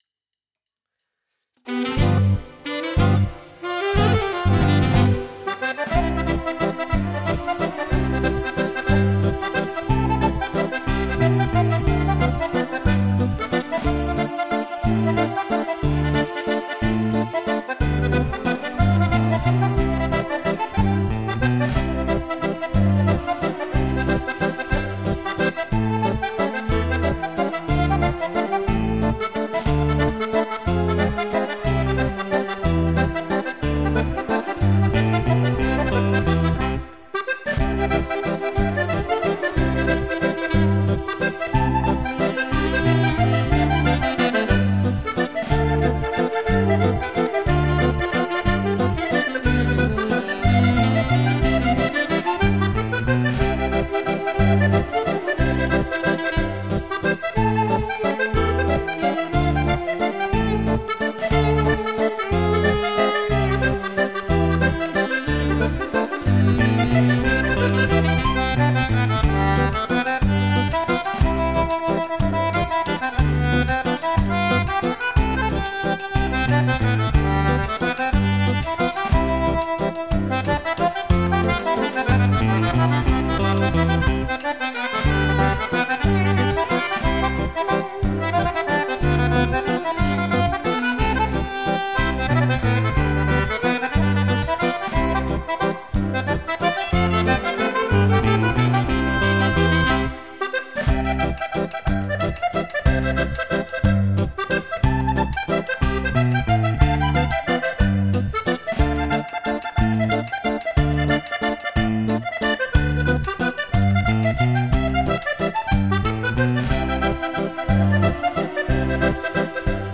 Valzer
Valzer per Organetto e Sax